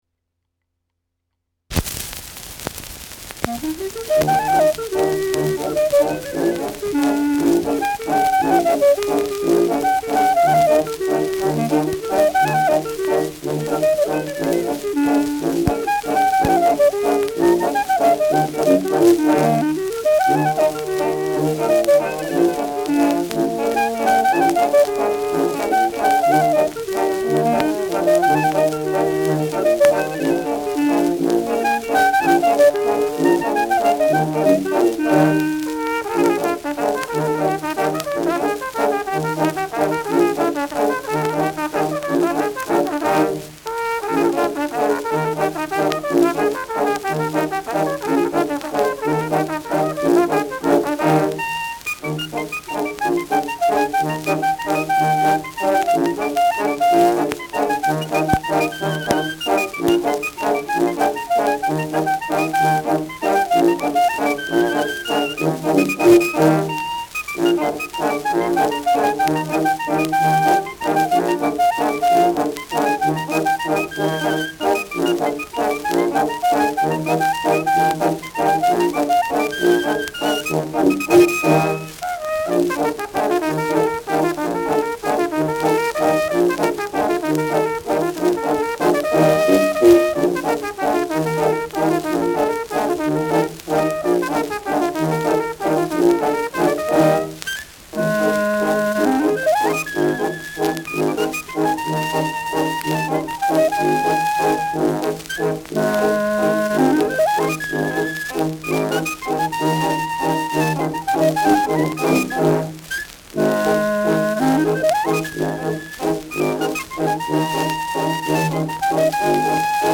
Schellackplatte
präsentes Knistern : abgespielt : leiert : leichtes Rauschen : gelegentliches Knacken
Stadtkapelle Weißenburg (Interpretation)